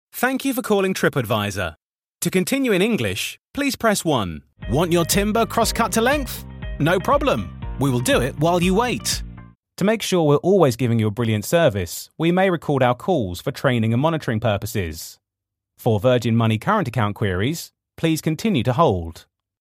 Inglés (Británico)
Comercial, Natural, Amable, Cálida, Empresarial
Telefonía